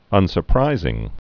(ŭnsər-prīzĭng)